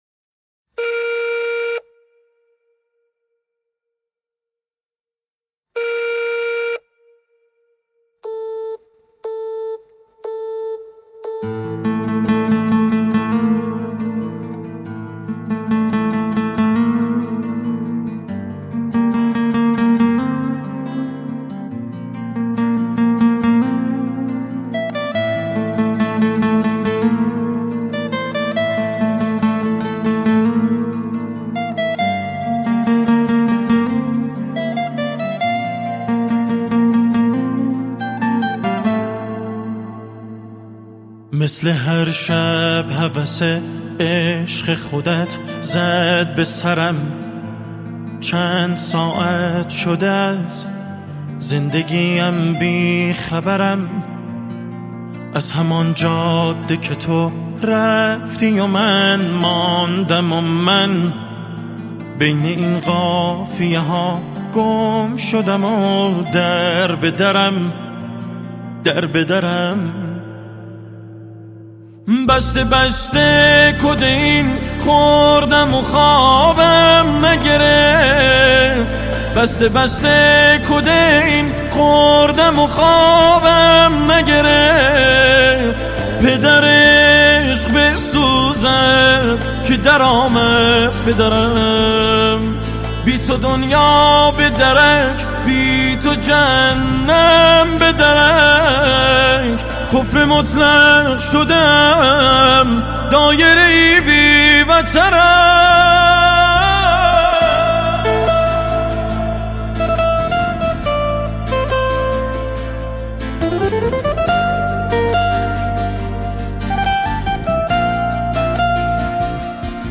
با ریتم 4/4